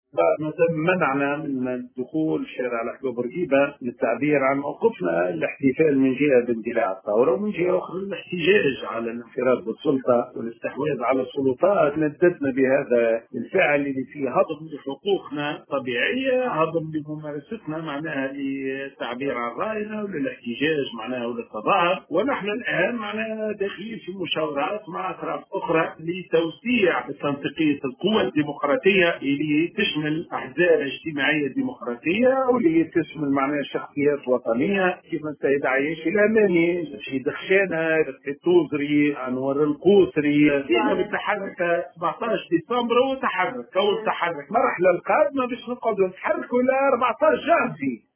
Dans une déclaration, accordée ce lundi 20 décembre 2021 à ”Tunisie Numérique”, Chaouachi a affirmé que suite à ce dépassement grave, il a été décidé de mener une série de consultations avec des parties nationales dont l’objectif escompté est de renforcer la force du collectif démocratique.